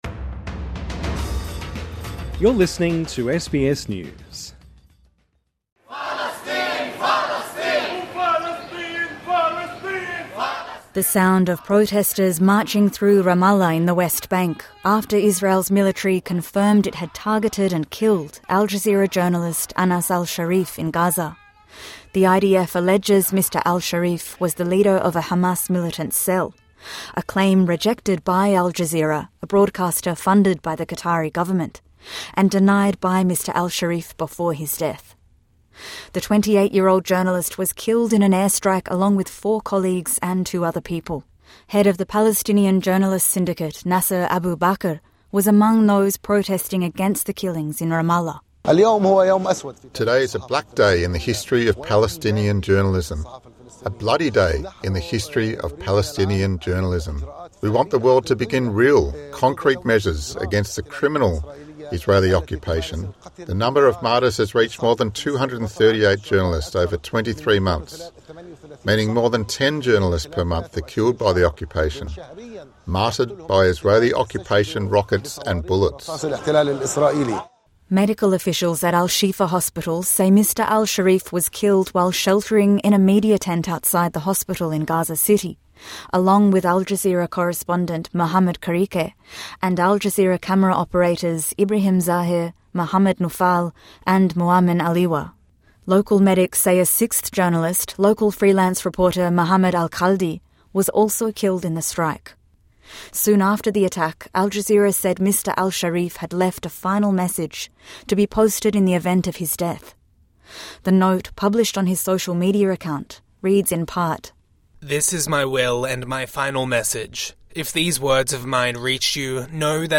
TRANSCRIPT The sound of protesters marching through Ramallah in the West Bank, after Israel's military confirmed it had targeted and killed Al Jazeera journalist, Anas Al Sharif in Gaza.